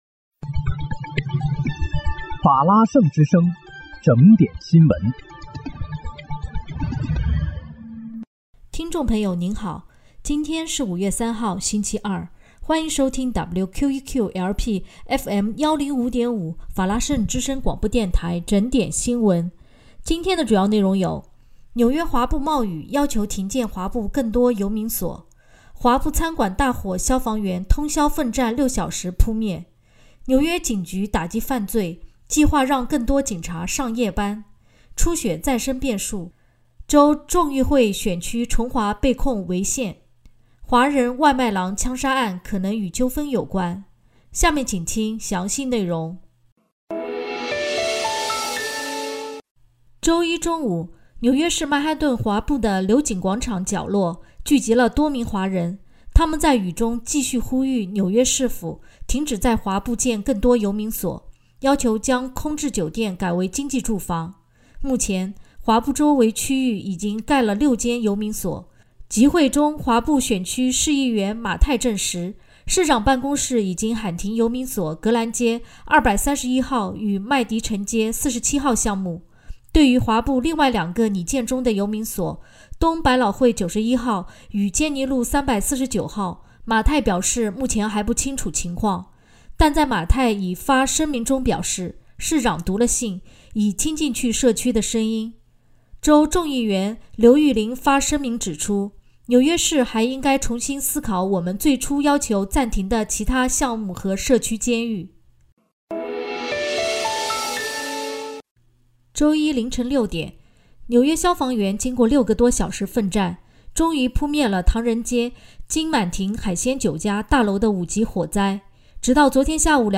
5月3日（星期二）纽约整点新闻